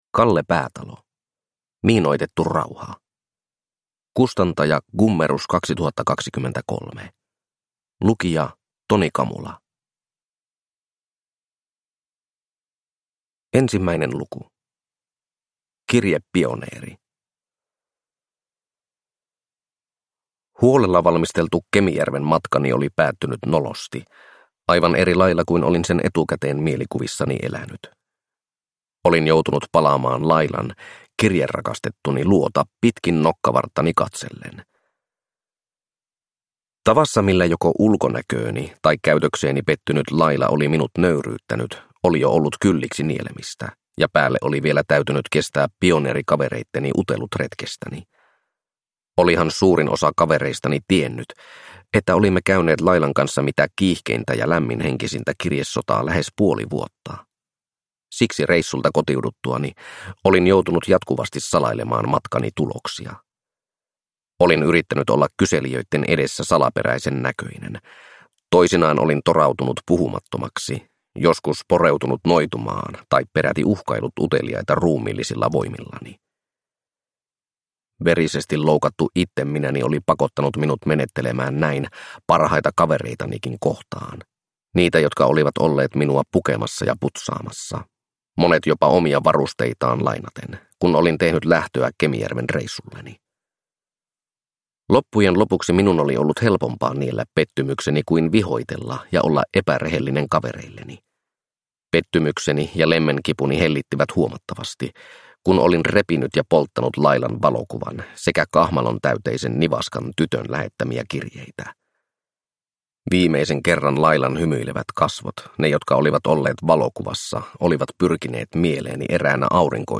Miinoitettu rauha – Ljudbok – Laddas ner